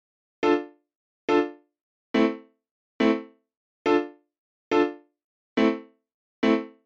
雷鬼钢琴
Tag: 70 bpm Reggae Loops Piano Loops 1.15 MB wav Key : Unknown